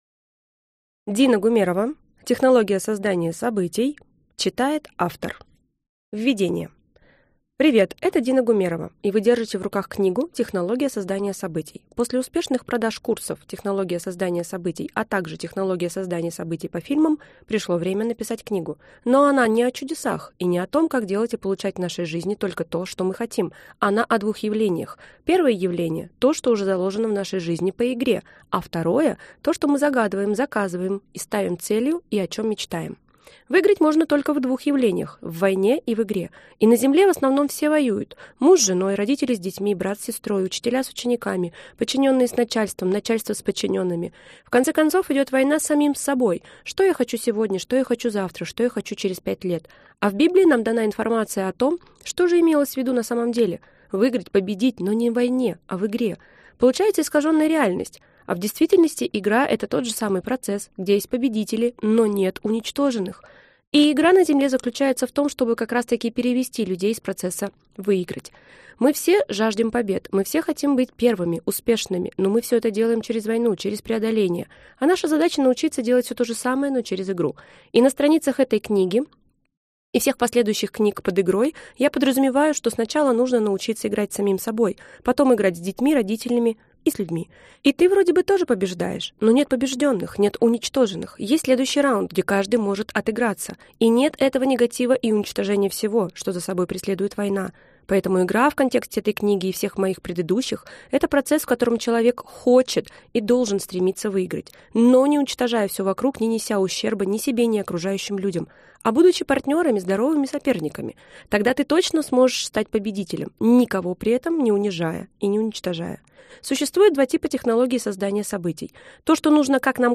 Аудиокнига Технология создания событий: методики управления своей жизнью | Библиотека аудиокниг